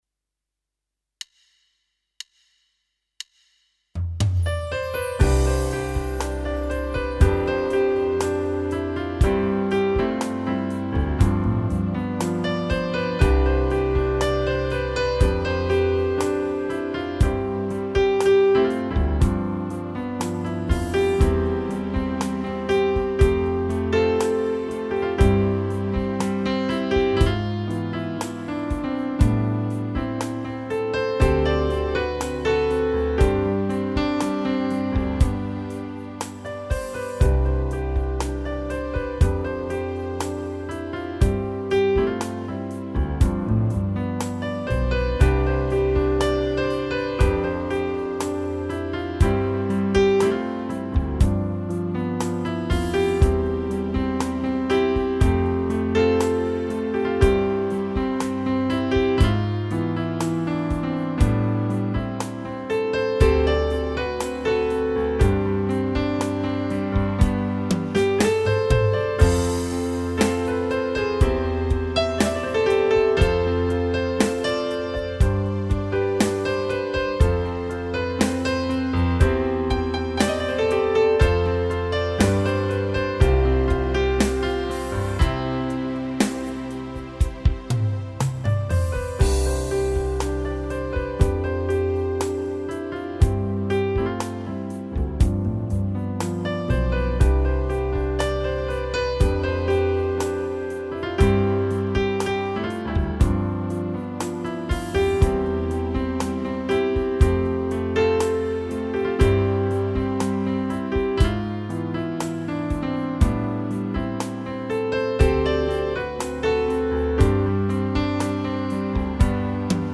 Eigene Kompositionen